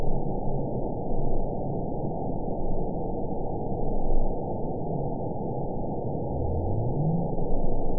event 911348 date 02/22/22 time 15:53:51 GMT (3 years, 3 months ago) score 9.48 location TSS-AB01 detected by nrw target species NRW annotations +NRW Spectrogram: Frequency (kHz) vs. Time (s) audio not available .wav